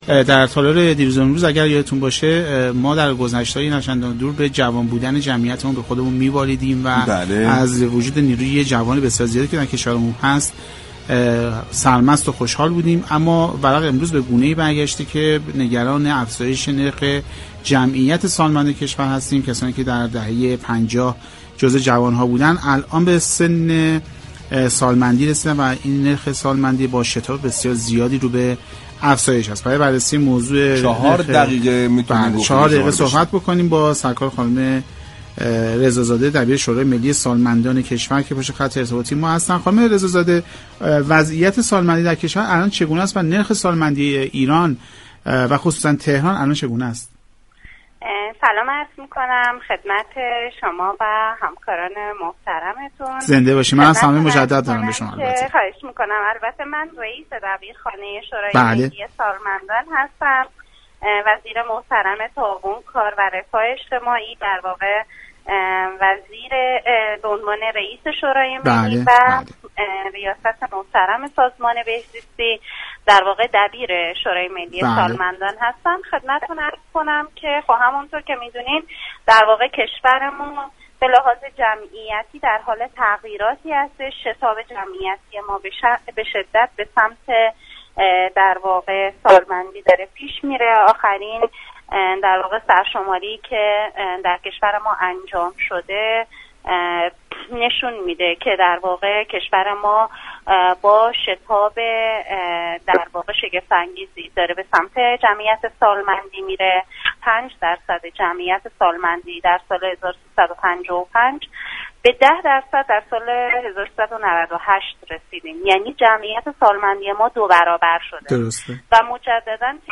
به گزارش پایگاه اطلاع رسانی رادیو تهران، مژگان رضازاده رئیس دبیرخانه شورای ملی سالمندان كشور در گفتگو با برنامه «سعدآباد» رادیو تهران با بیان اینكه كشور ما به لحاظ جمعیتی در حال تغییر است گفت: بر اساس آخرین سرشماری؛ جمعیت كشورمان با سرعت به سمت سالمندی پیش می‌رود.